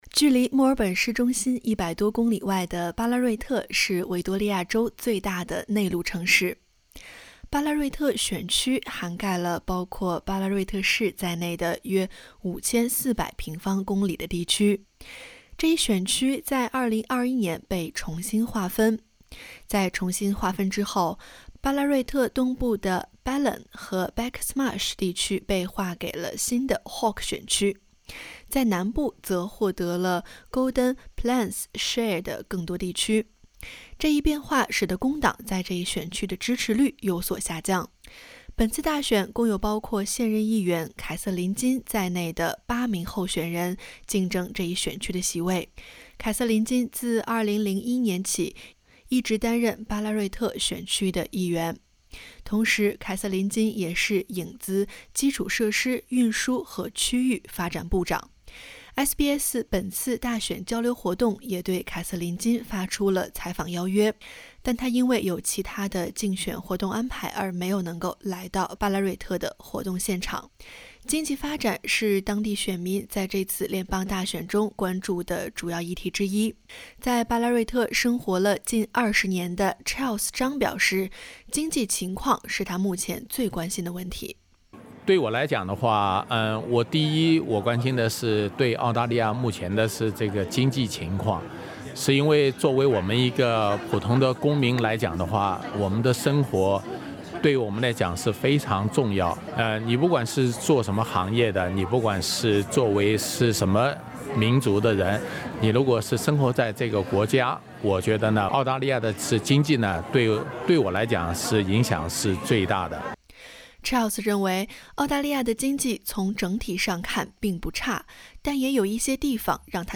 联邦大选将至，SBS电台于5月7日在维多利亚州的Ballarat选区，举办了一场选举交流会，聆听Ballarat选区候选人与选民的心声。 （点击首图收听采访音频）